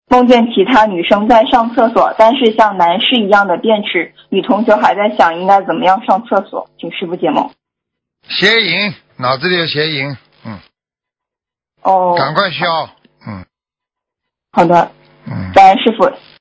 但因对话交流带有语气，文字整理不可能完全还原情境。